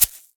Shaken Shaker 02.wav